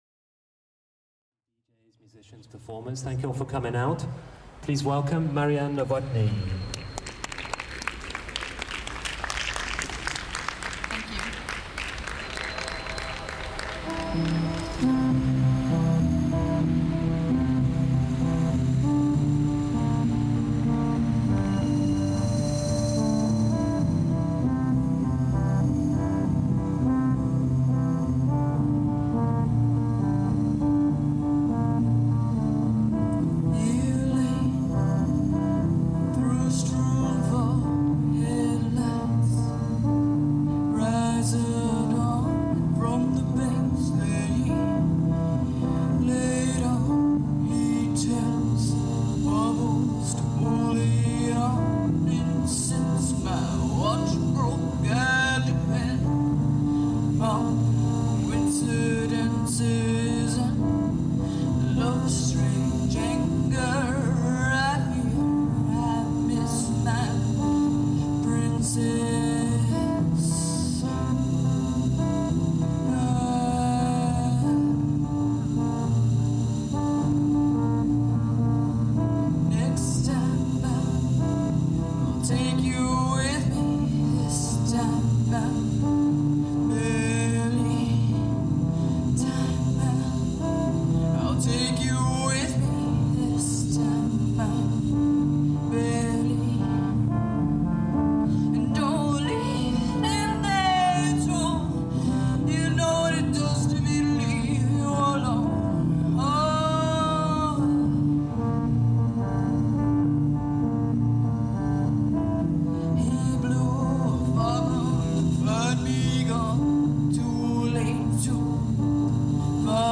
"LIVE VERSION"